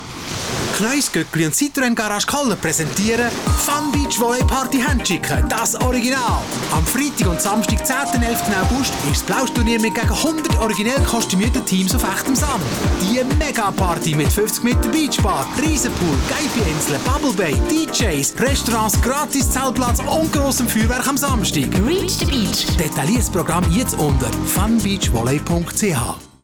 Radioclip
Unsere Werbung im Radio Argovia.